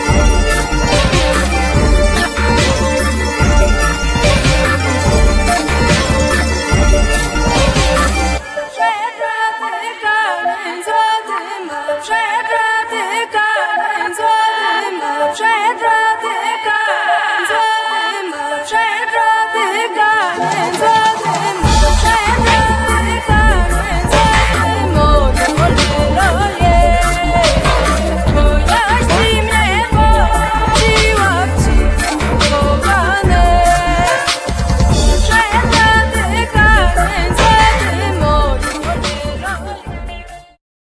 radosne, żywiołowe, roztańczone.